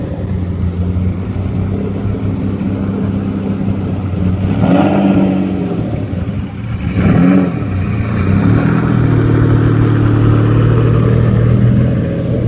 Download Free Lamborghini Diablo Sound Effects
Lamborghini Diablo